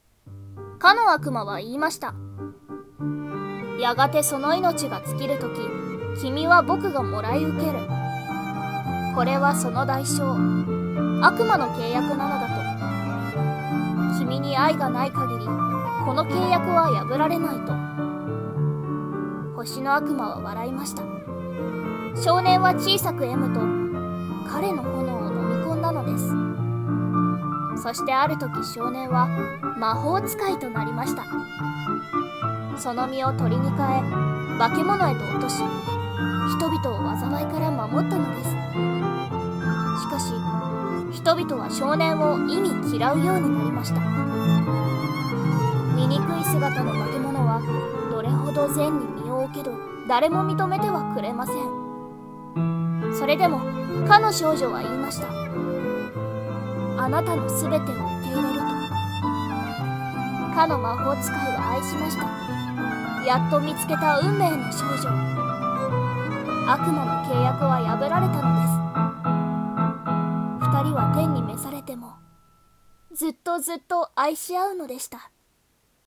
【一人声劇】星の悪魔